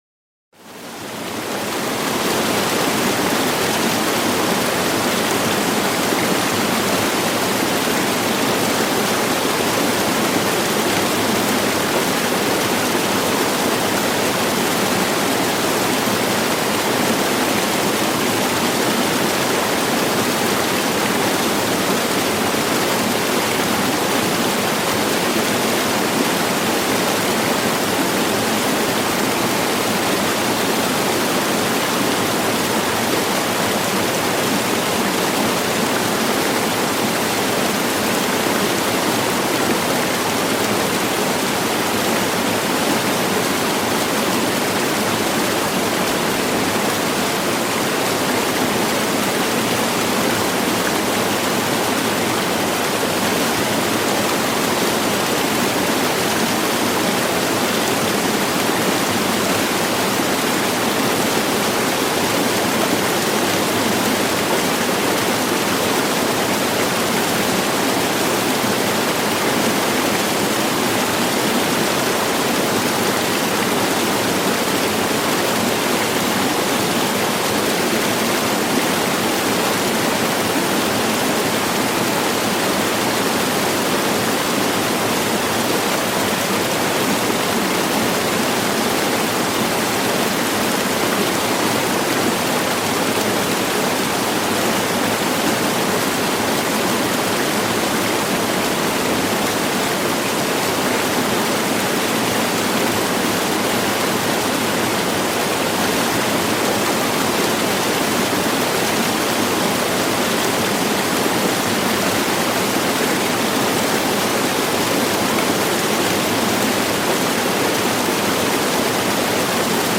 SOFORT-ENTSPANNUNG: Waldwasserfall-Gebirgsstille mit Bach-Rauschen
Naturgeräusche